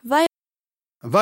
Gravona et Rocca (sud)